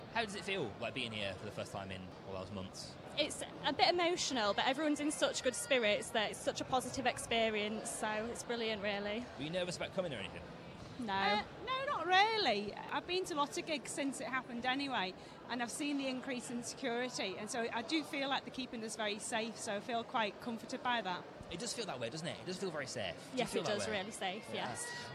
We caught up with fans to find out what the atmosphere was like at Manchester Arena